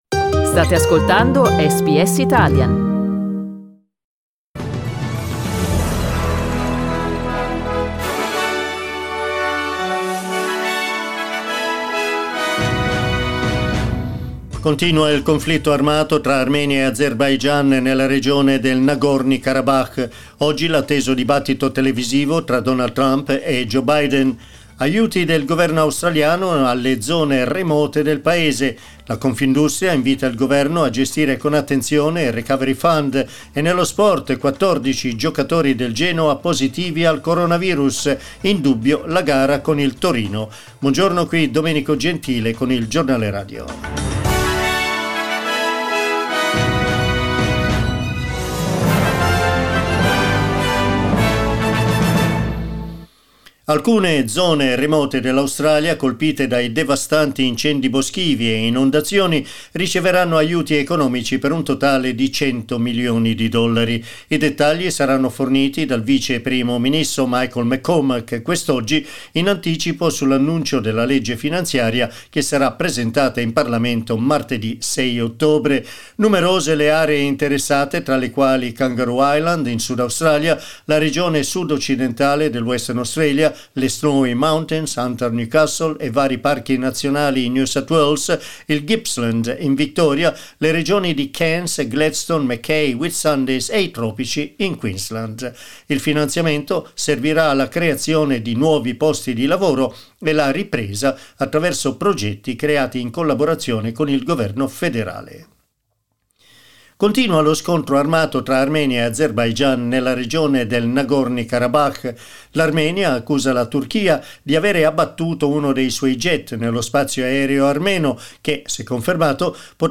News bulletin in Italian broadcast this morning at 09:00am.